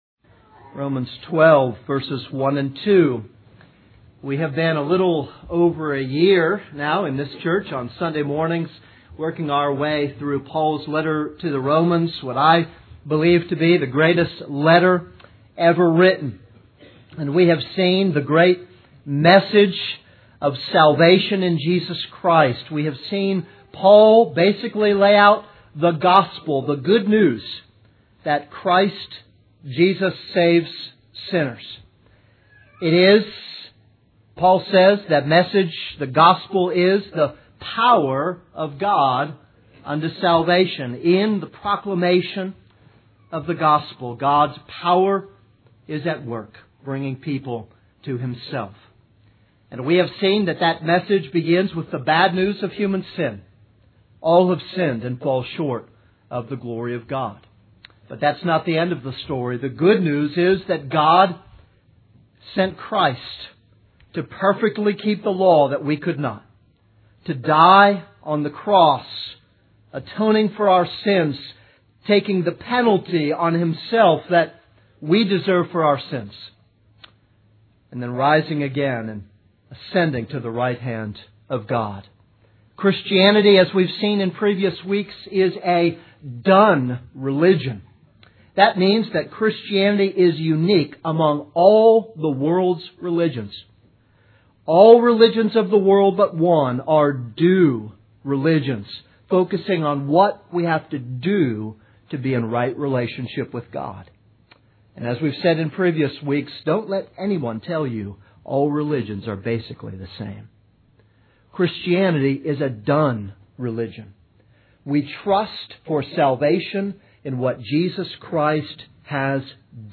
This is a sermon on Romans 12:1-2.